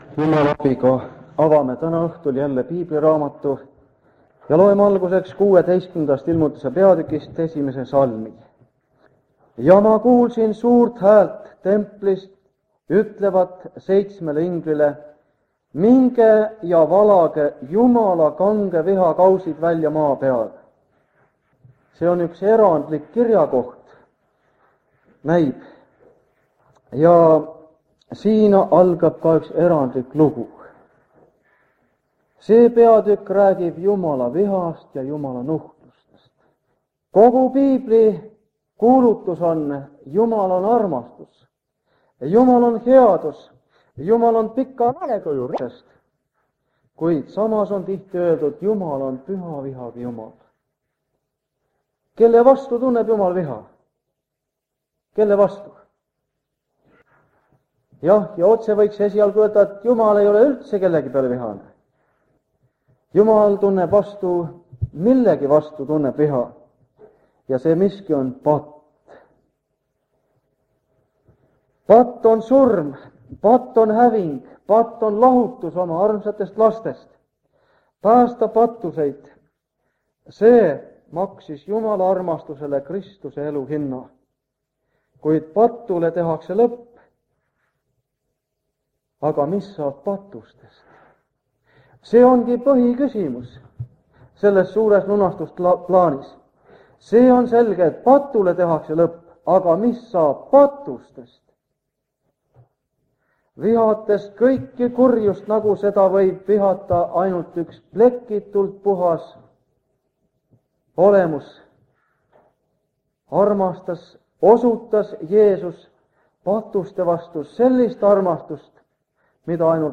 Jutlused
Ilmutuse raamatu seeriakoosolekud Kingissepa linna adventkoguduses